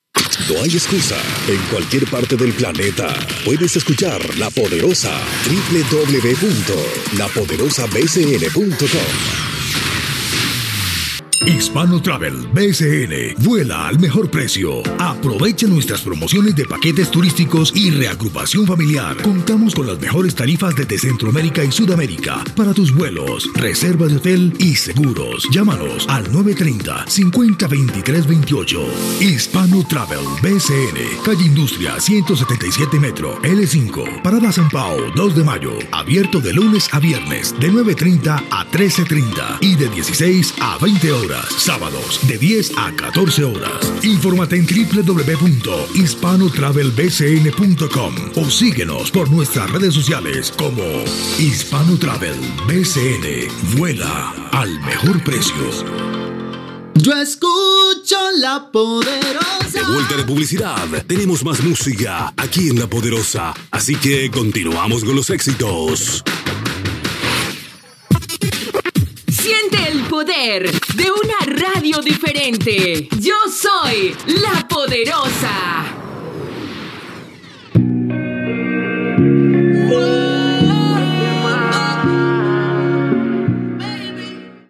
Indicatiu, publciitat, identificació i tema musical
Entreteniment